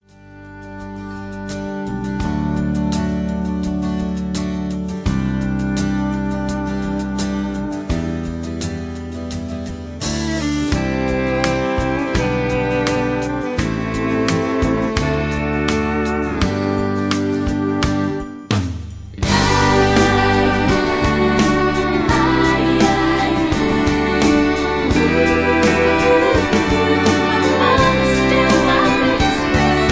Karaoke Mp3 Backing Tracks